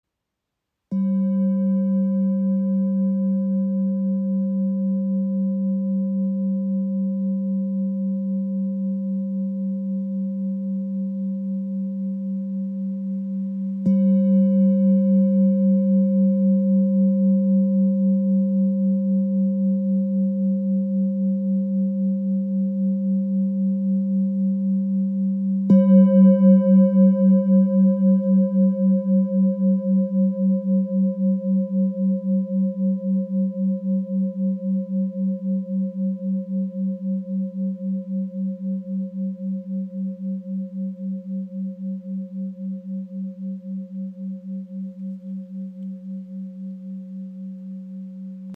Tibetische Klangschale 19.5 cm – klarer Ton G aus Nepal • Raven Spirit
Handgefertigte Klangschale mit Blume des Lebens und Mani Mantra. Klarer, erdender Ton G – ideal für Meditation, Klangarbeit und achtsame Stille.
Klangbeispiel
Ihr obertonreicher Klang im Ton G ist klar und erdend.